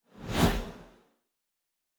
pgs/Assets/Audio/Sci-Fi Sounds/Movement/Fly By 01_1.wav at master
Fly By 01_1.wav